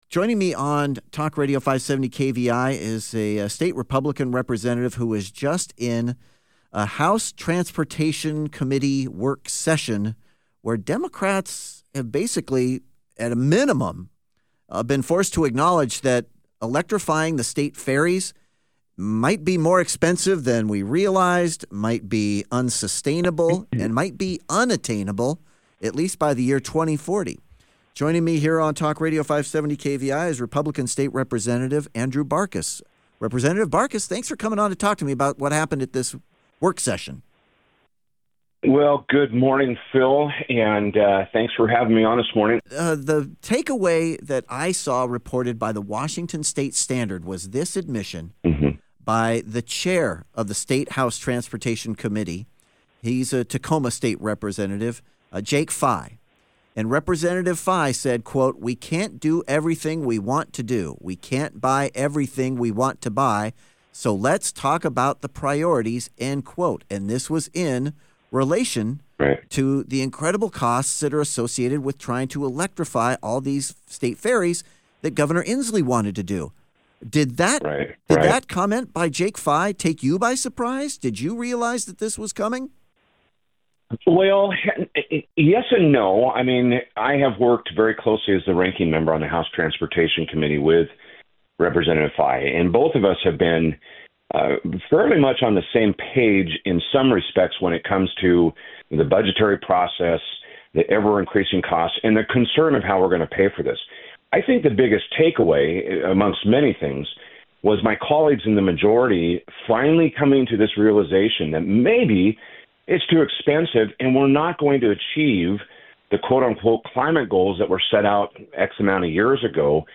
Rep. Andrew Barkis joined The Morning Ride to share his findings indicating they will only be a money pit incapable of meeting our states needs.